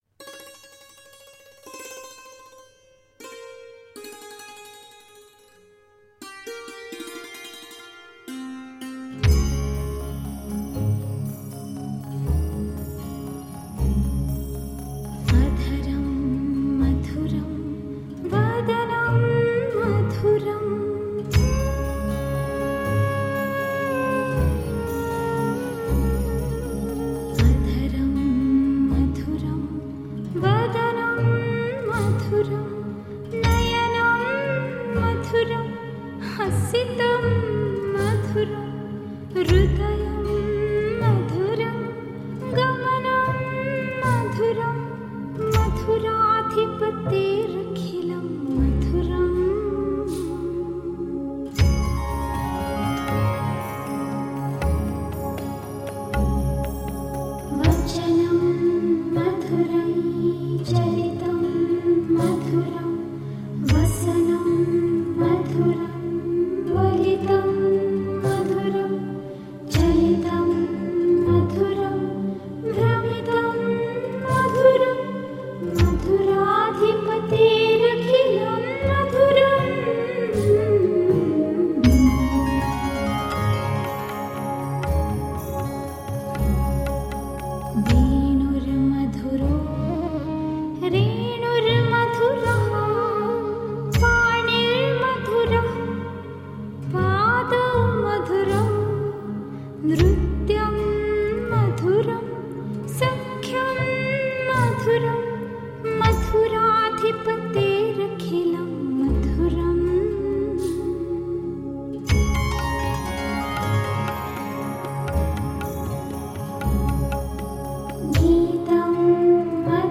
Ancient indian songs in a modern blend.
dulcet and delicate voice
Tagged as: World, Indian, Indian Influenced, Sitar